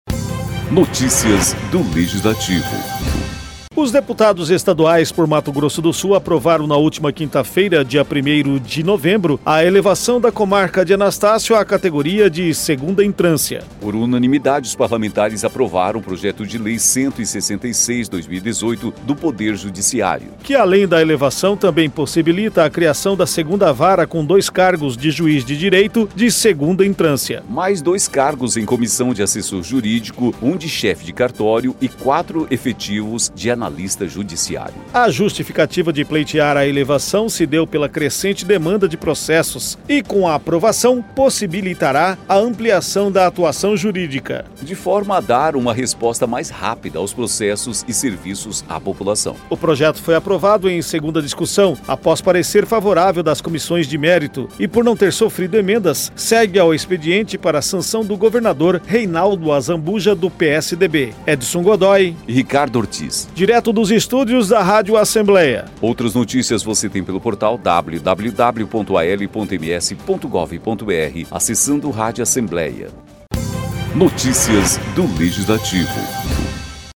Locução: